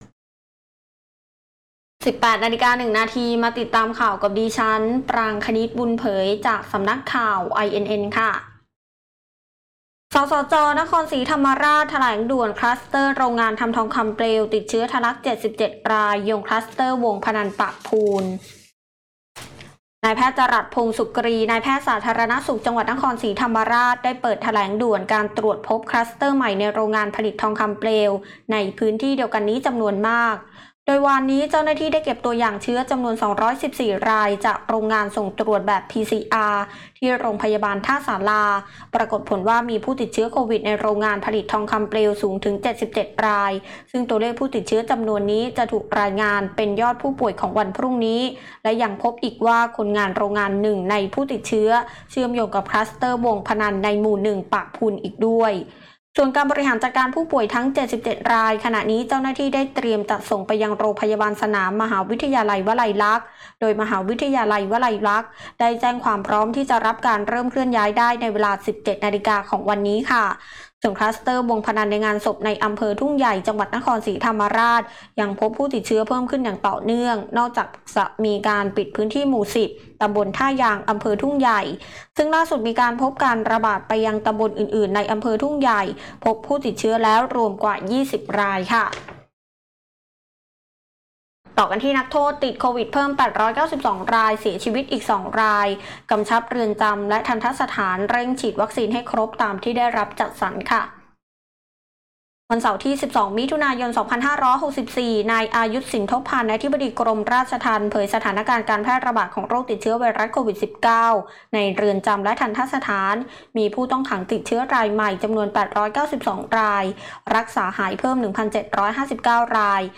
คลิปข่าวต้นชั่วโมง
ข่าวต้นชั่วโมง 18.00 น.